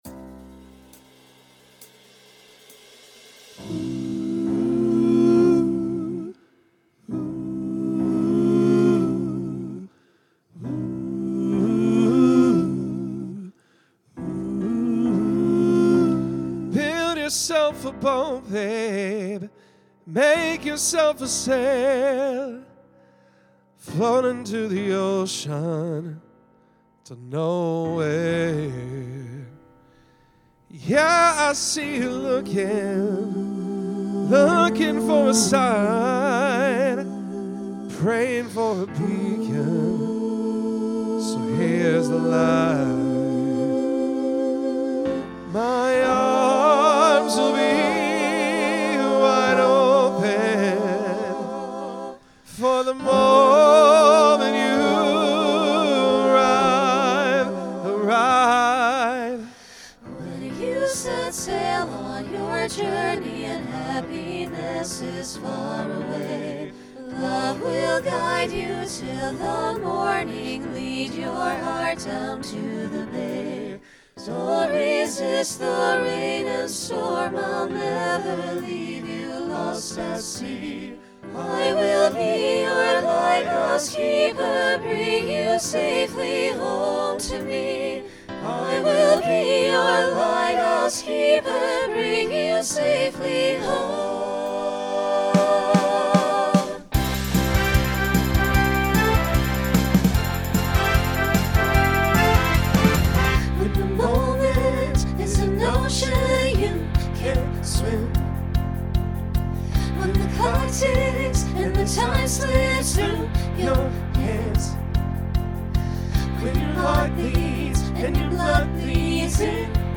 Genre Pop/Dance , Rock Instrumental combo
Voicing SATB